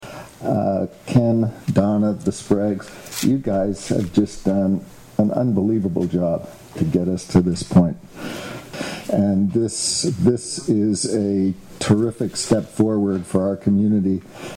Prince Edward County Mayor Steve Ferguson speaks at the key exchange ceremony at the former Pinecrest Memorial Elementary School in Bloomfield on October 5, 2020.